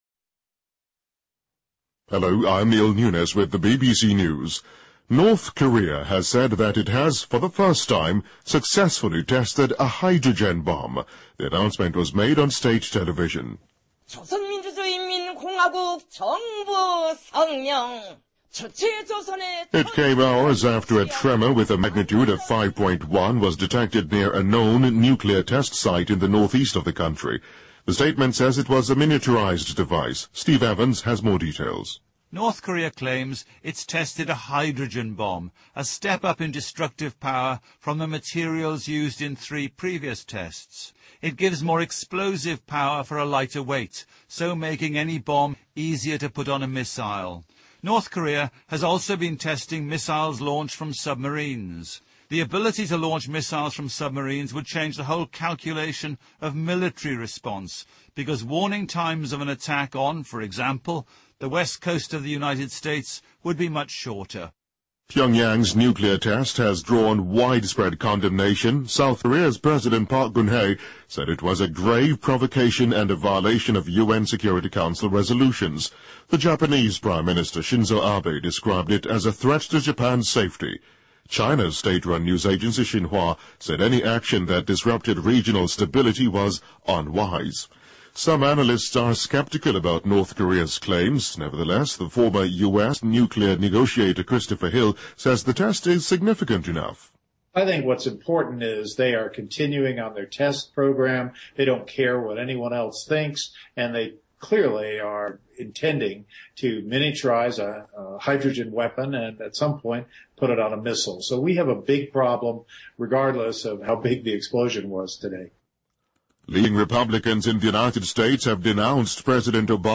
BBC news,朝鲜称氢弹试验成功